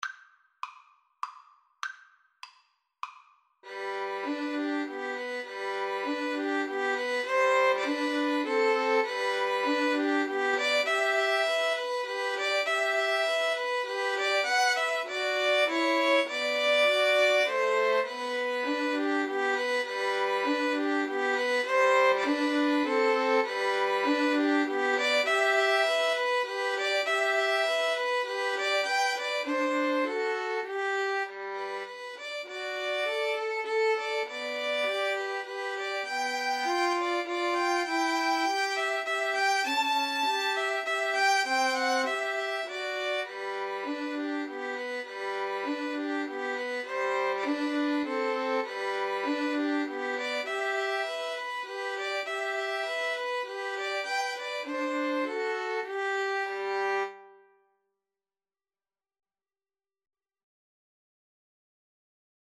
It is written in a simple waltz style.
Moderato
Violin Trio  (View more Easy Violin Trio Music)
brahms_waltz_3VLN_kar3.mp3